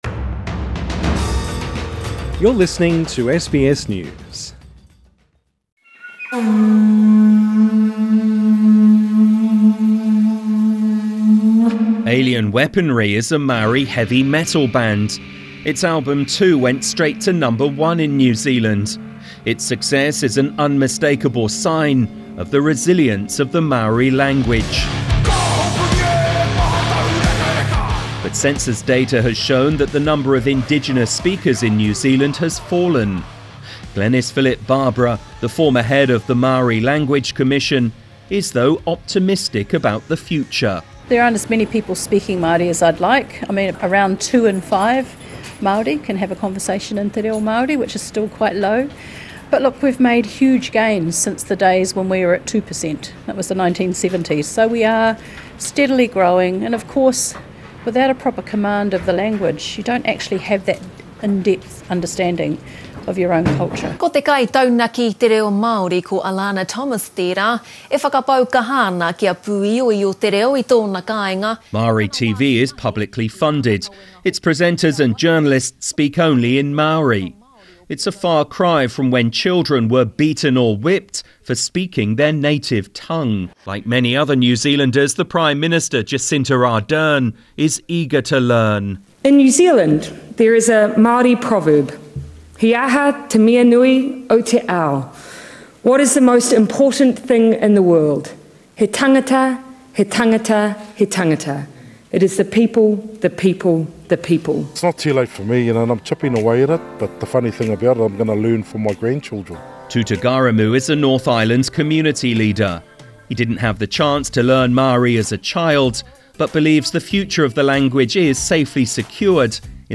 This report originally produced by the BBC Share